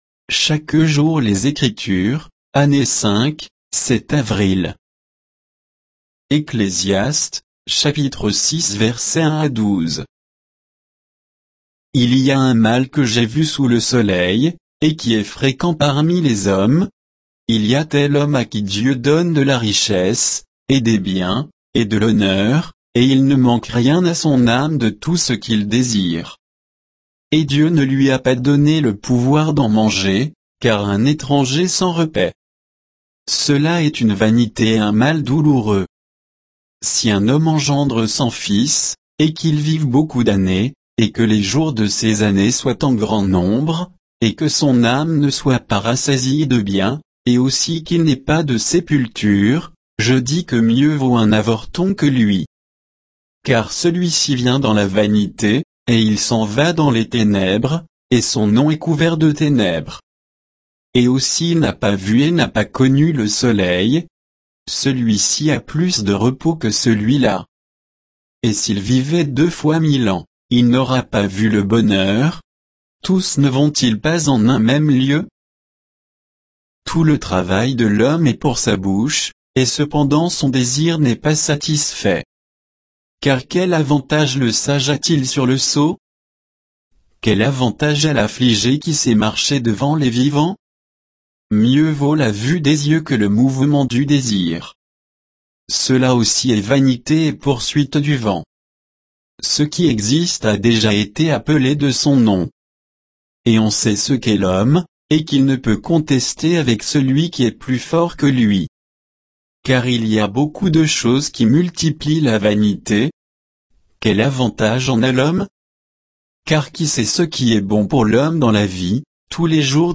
Méditation quoditienne de Chaque jour les Écritures sur Ecclésiaste 6, 1 à 12